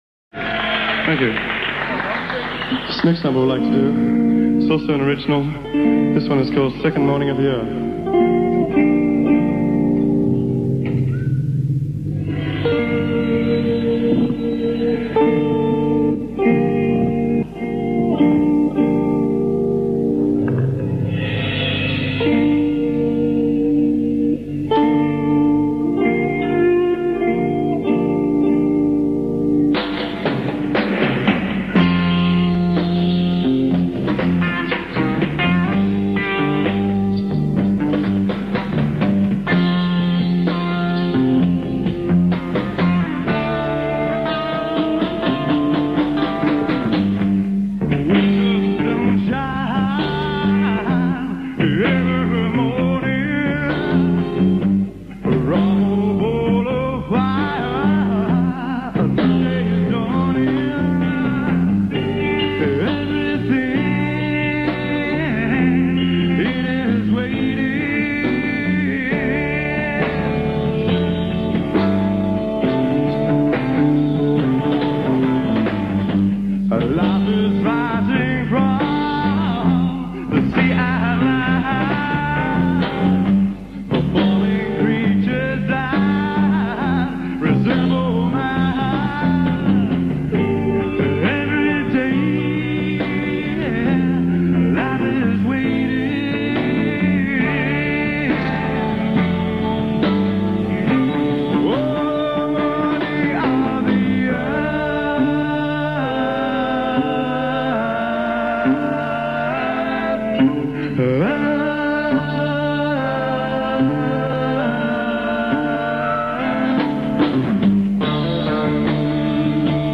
keyboardist